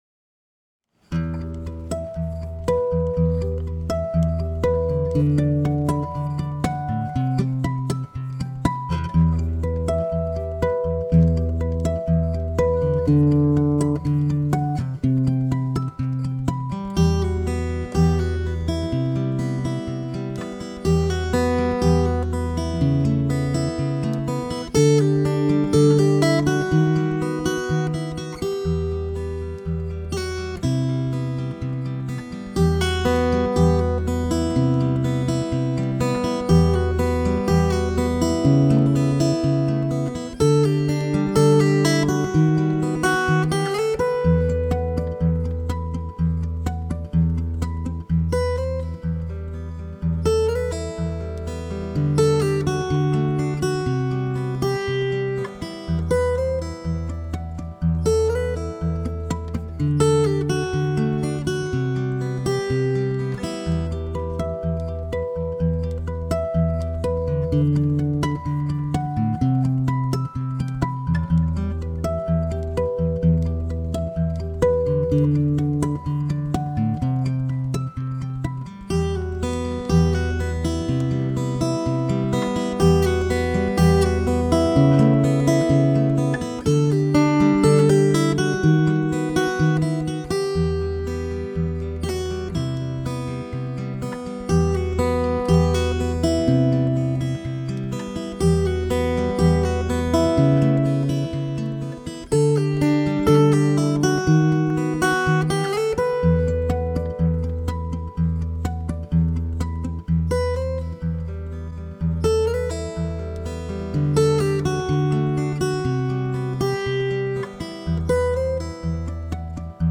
Не лучшим образом записана, но для первой версии сойдёт.